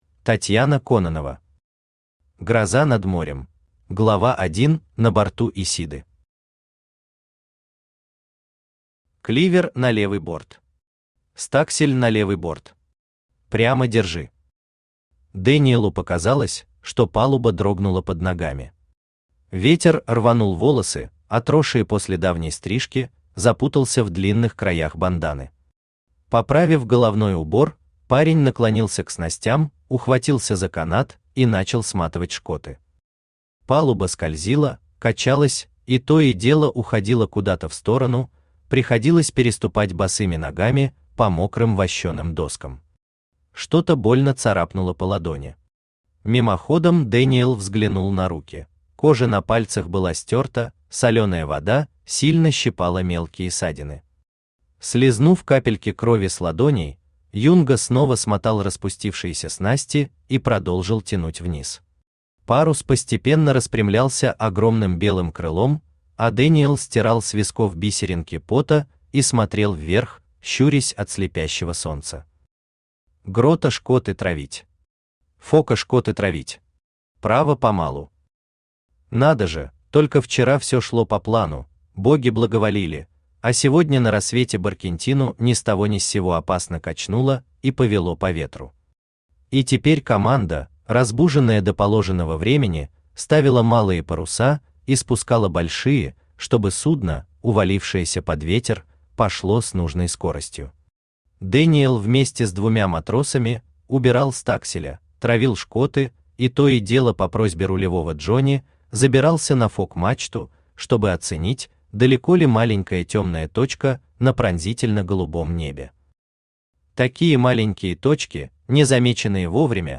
Аудиокнига Гроза над морем | Библиотека аудиокниг
Aудиокнига Гроза над морем Автор Татьяна Кононова Читает аудиокнигу Авточтец ЛитРес.